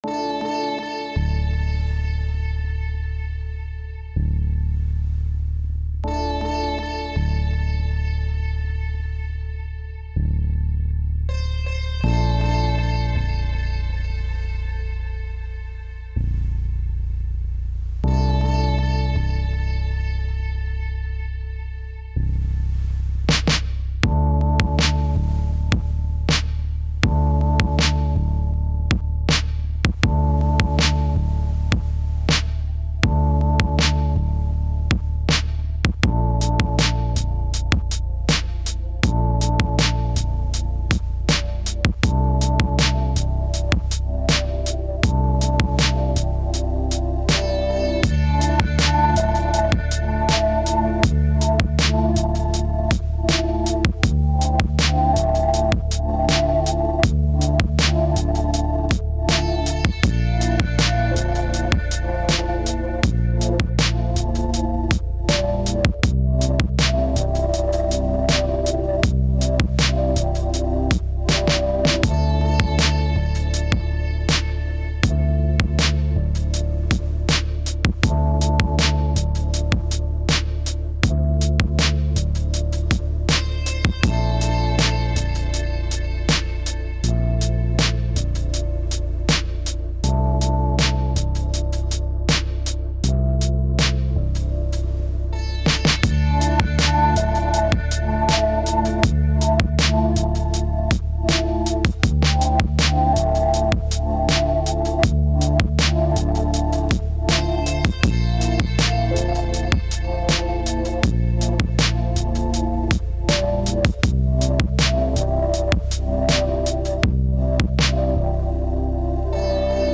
Genre: loop.